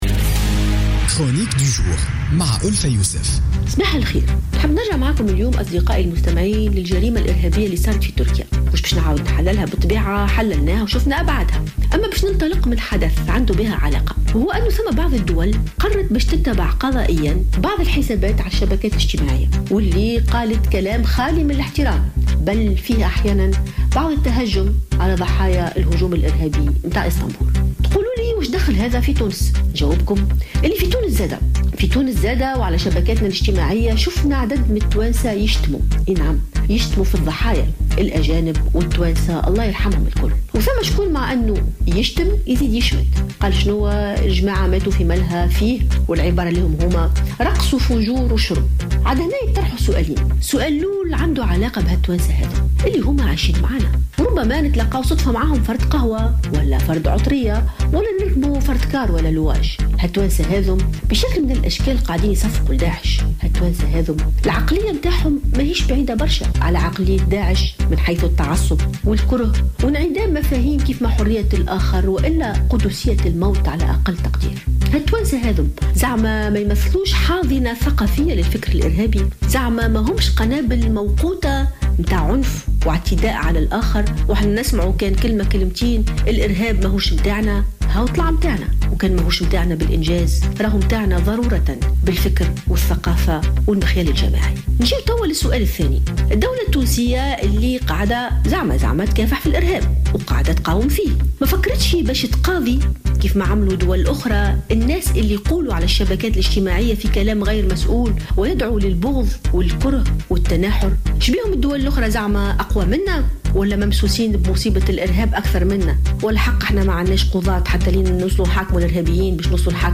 وأوضحت في افتتاحيتها لـ "الجوهرة اف أم" أن بعض الدول قررت ملاحقة قضائيا بعض المواقع الحسابية بسبب ردود أفعال تحرض على العنف على العملية الارهابية التي ارتكبت مؤخرا في ملهى سياحي بتركيا.